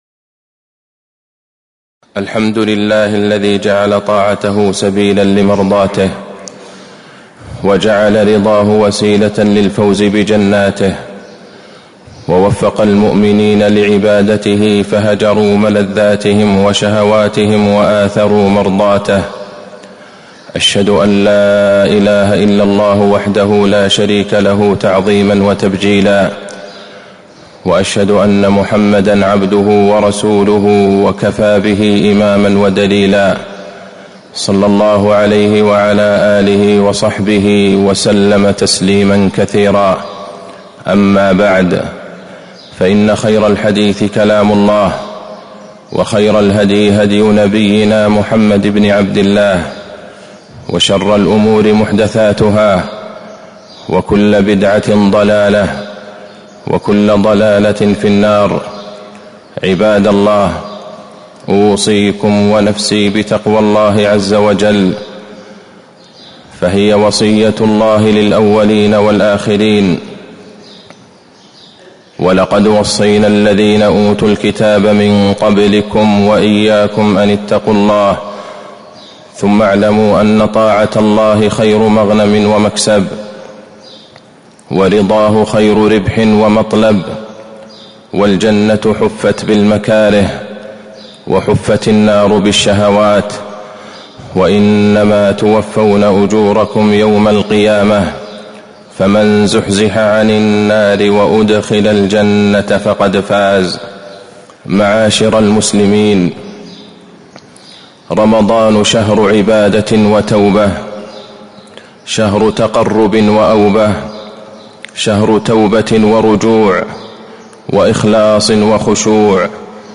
تاريخ النشر ٢٦ رمضان ١٤٤٥ هـ المكان: المسجد النبوي الشيخ: فضيلة الشيخ د. عبدالله بن عبدالرحمن البعيجان فضيلة الشيخ د. عبدالله بن عبدالرحمن البعيجان الوصية في ختام رمضان